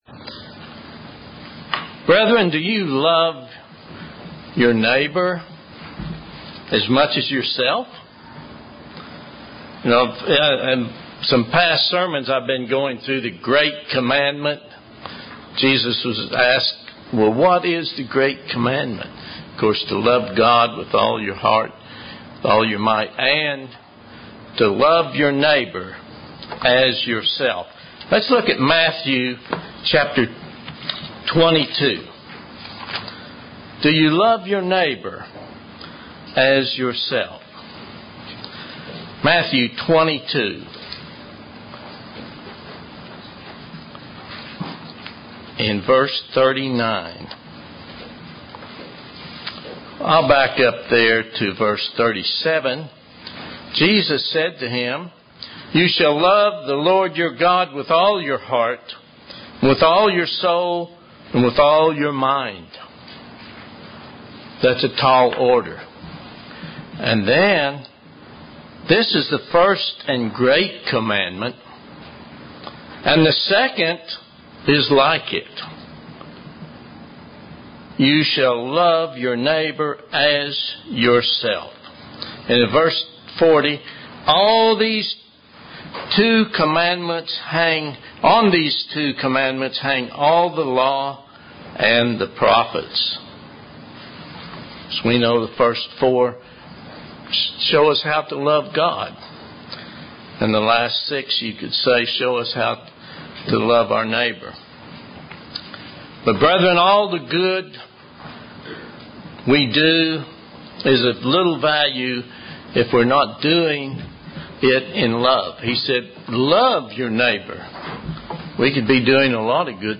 Given in Oklahoma City, OK
UCG Sermon Studying the bible?